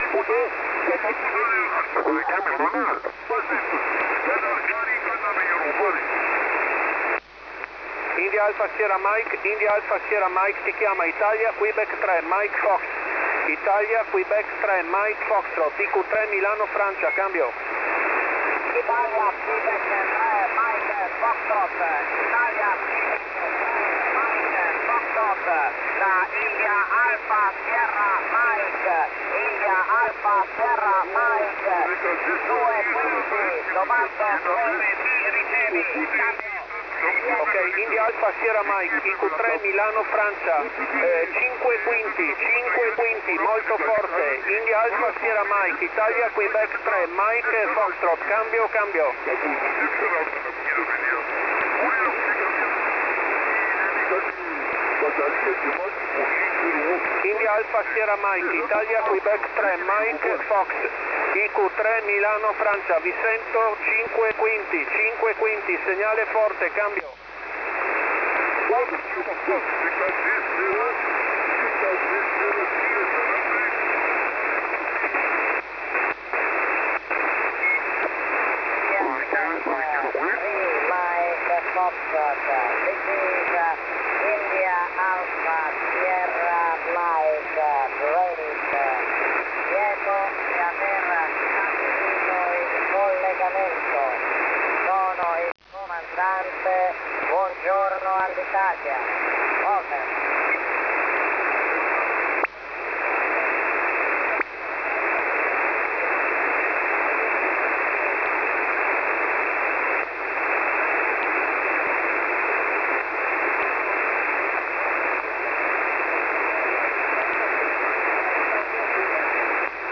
Scarica la registrazione del collegamento (File wav)